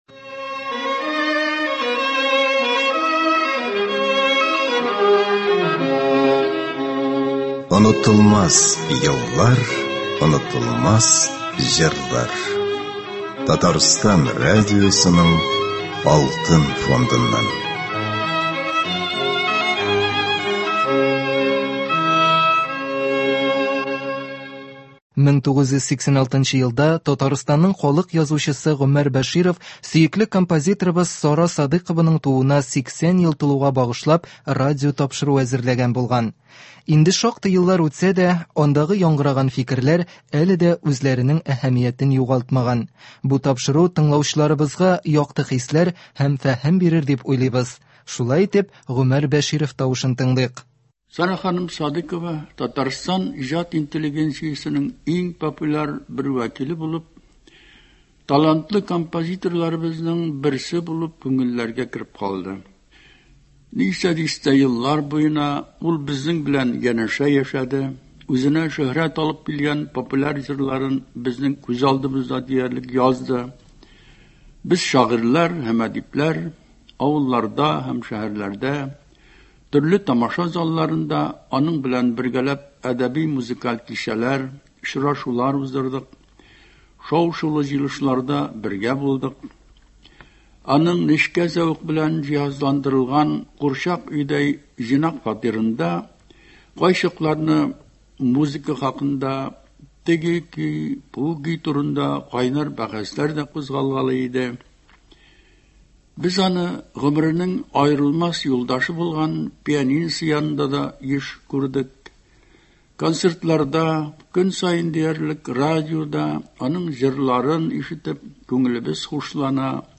Хәзер игътибарыгызга Татарстанның халык язучысы Гомәр Бәшировның Сара Садыйкова иҗатына багышлап әзерләгән радиоочеркы тәкъдим ителә. 1989 елда ясалган әлеге язма радиобыз фондында кадерләп саклана.